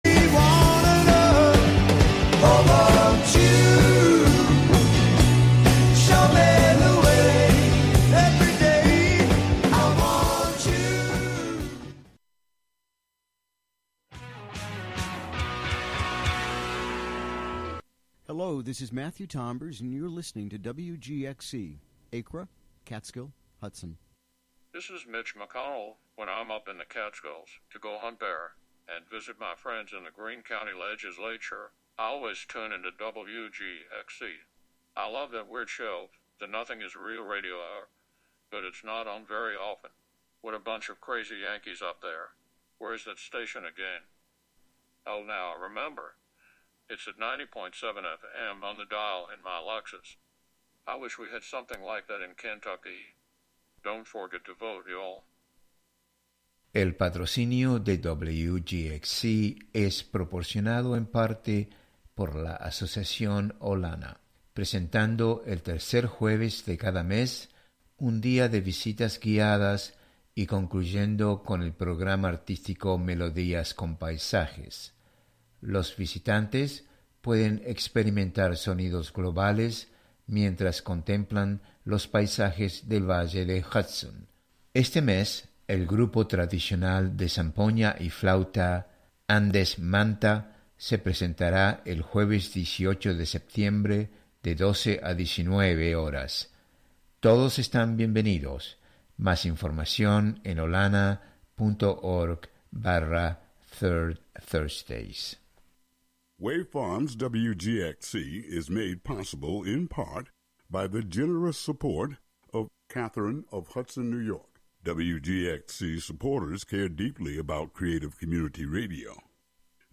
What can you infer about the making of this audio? In this show, we explore fermentation in Ireland through songs, anecdotes, memories and histories. It was inspired by a recent road trip there.